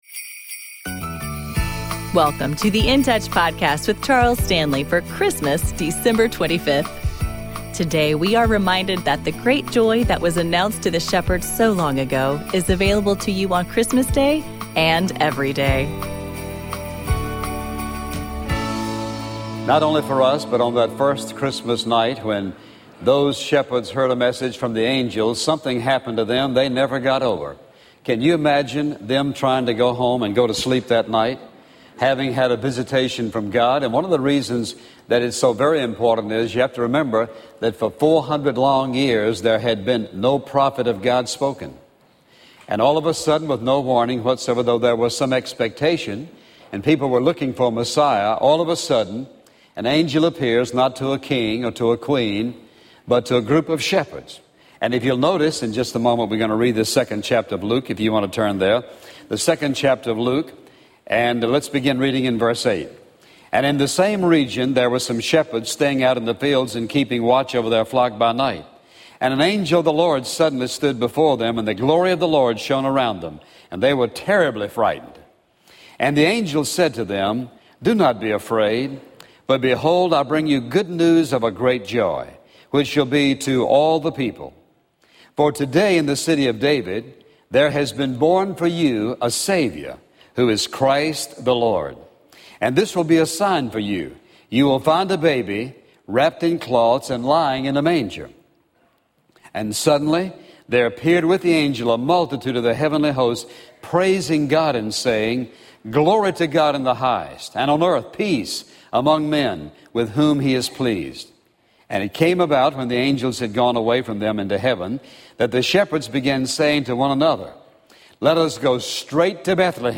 Daily Radio Program